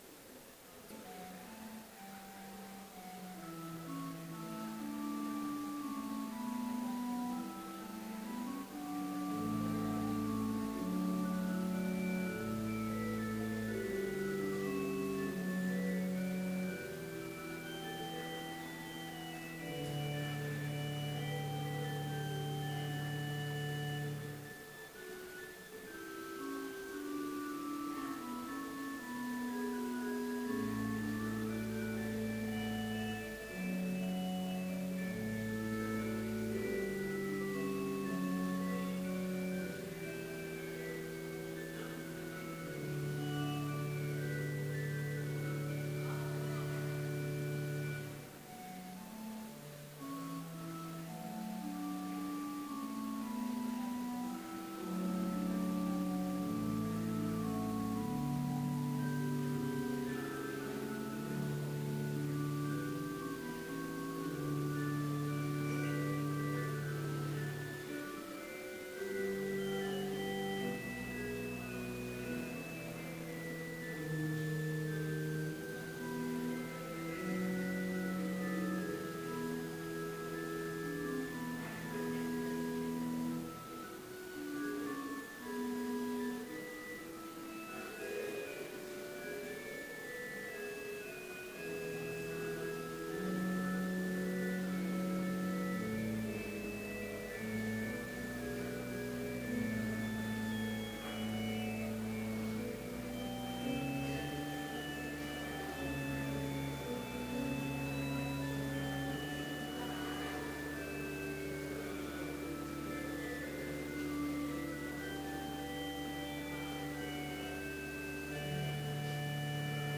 Complete service audio for Chapel - April 6, 2016